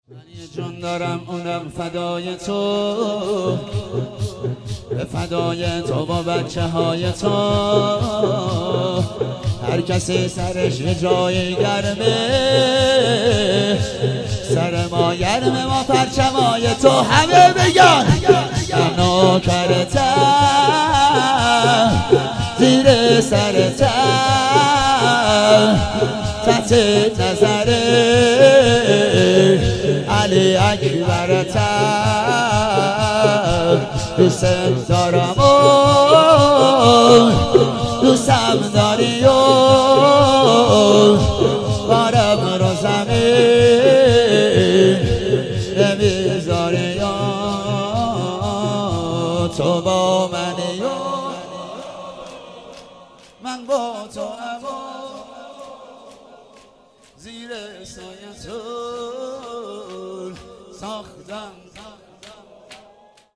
هفتگی 11 بهمن 97 - شور - من یه جون دارم اونم فدای تو